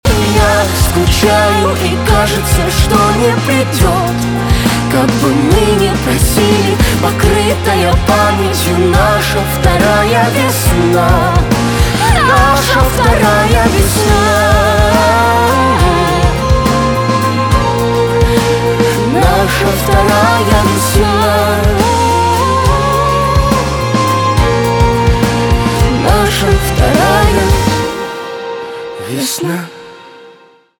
русский рок
саундтрек , грустные , красивые , чувственные
скрипка